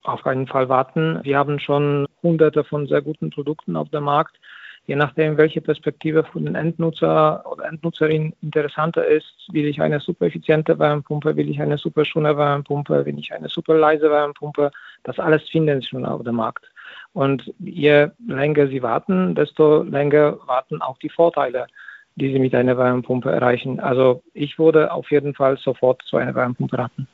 Interview mit Freiburger Forscher: "Ich würde sofort zu einer Wärmepumpe raten"